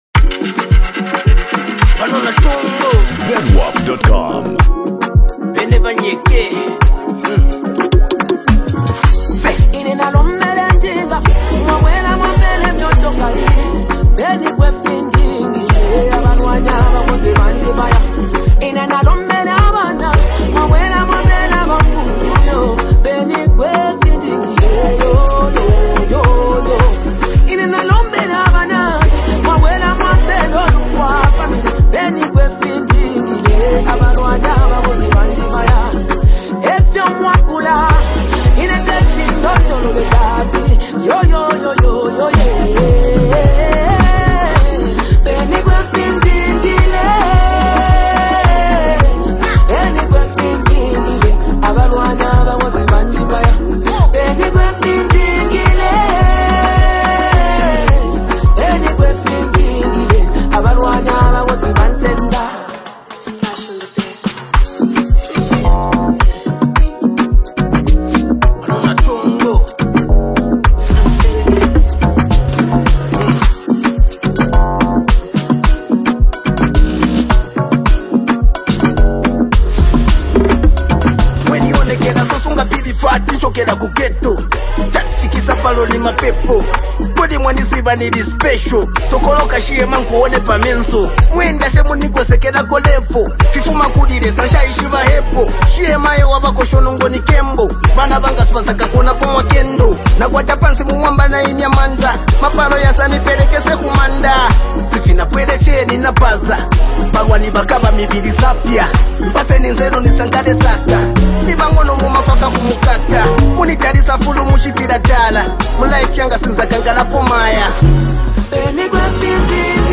Genre: Afro-beats, Zambia Songs